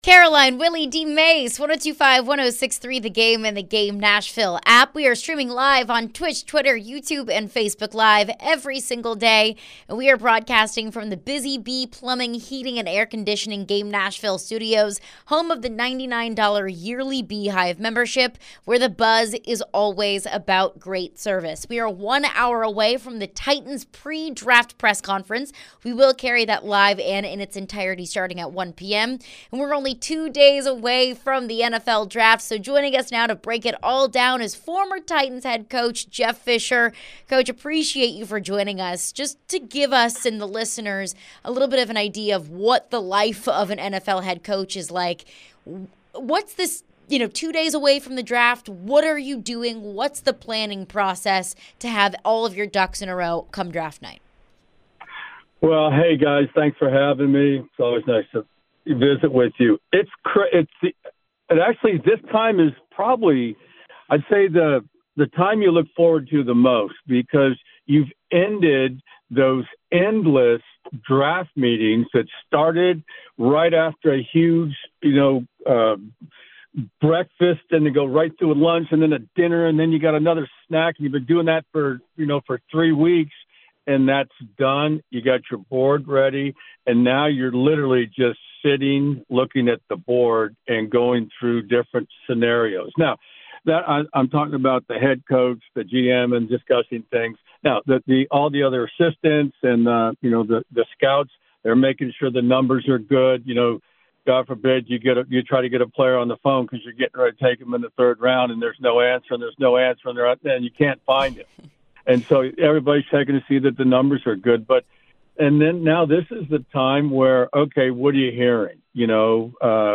joined by the Nashville Kats President of Operations and former Tennessee Titans head coach Jeff Fisher